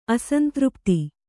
♪ asantřpti